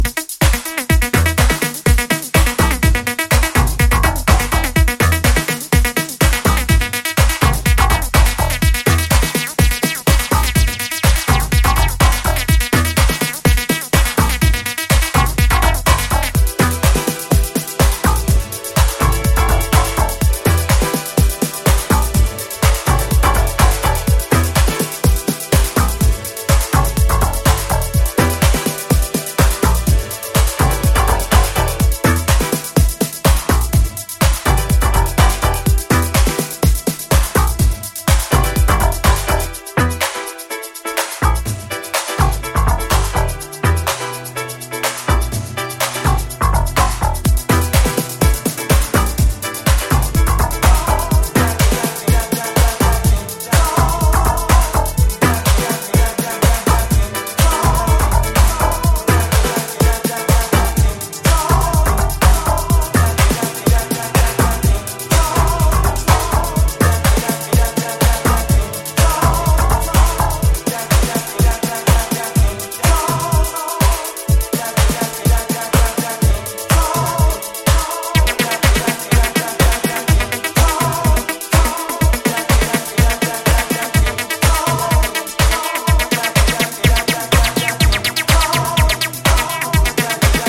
House Acid Chicago